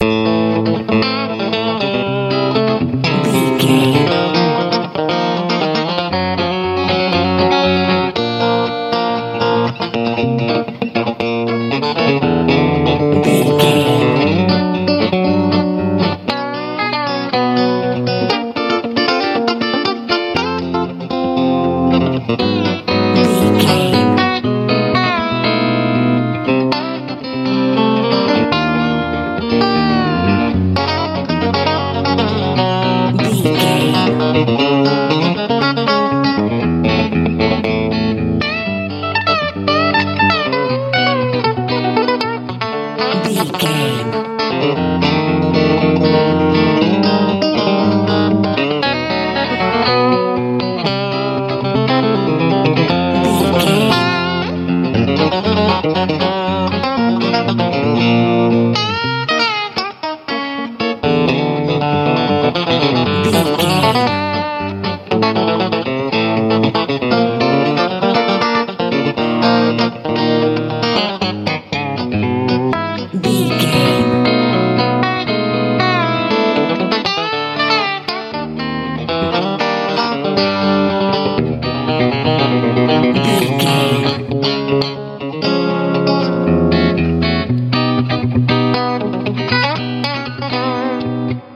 rockable feel
Ionian/Major
cool
bright
electric guitar
80s
90s